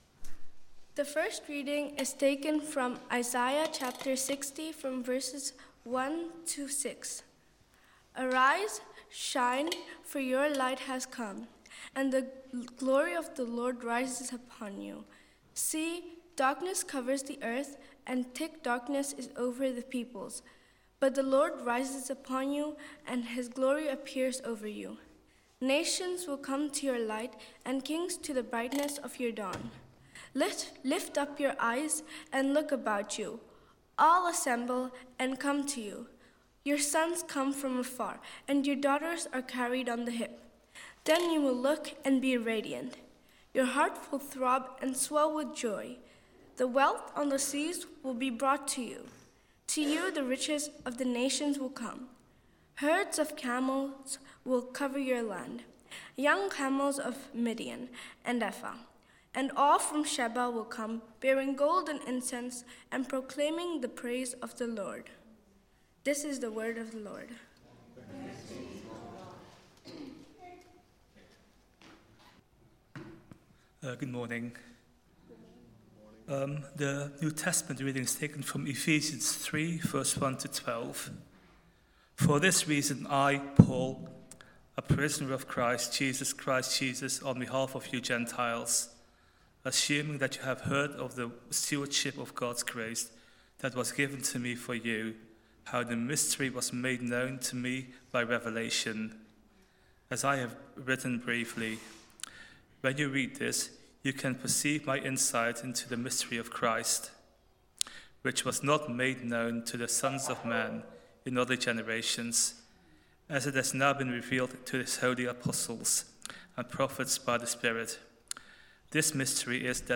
Weekly sermons from Trinity Church Eindhoven in the Netherlands.
TCE_Sermon-January-4-2025.mp3